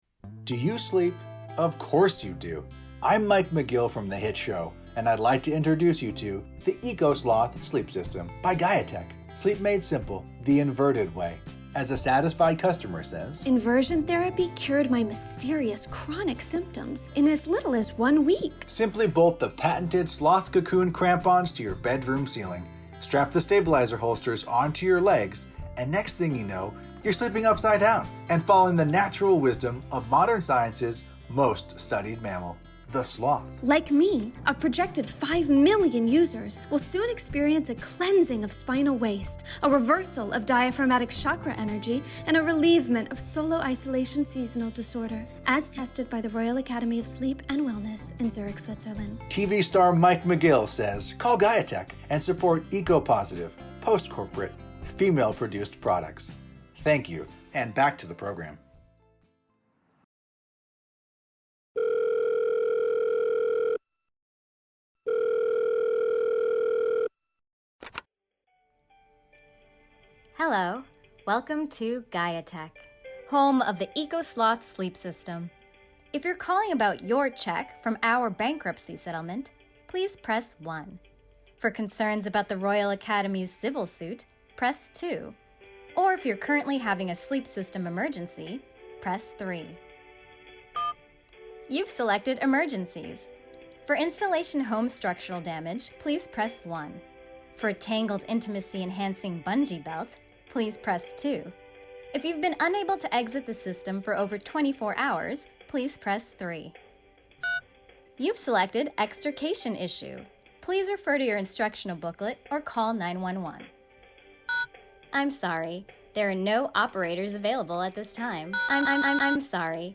Radio Play, 2020
Three segments from Public Assembly's first ever radio program (created during quarantine)